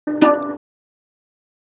Cajabirimbao_tonicaestirada_D4_mf.wav